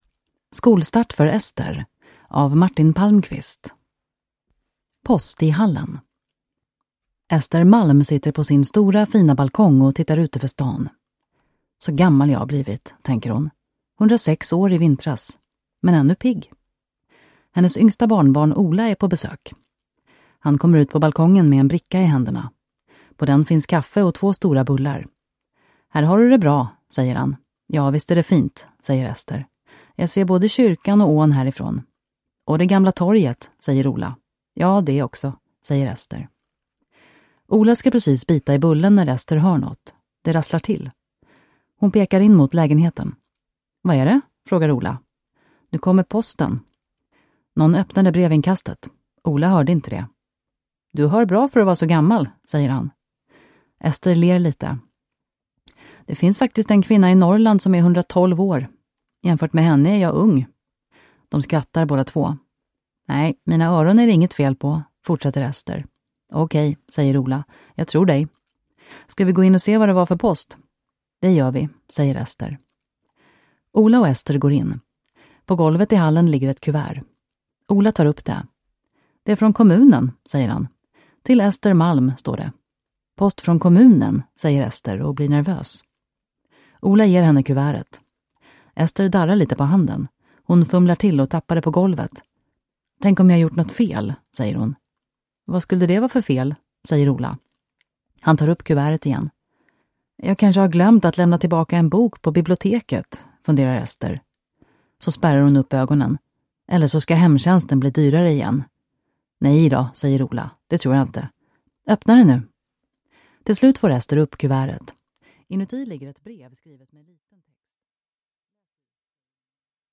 Skolstart för Ester – Ljudbok